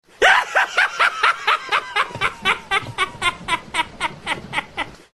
Category: Scary Ringtones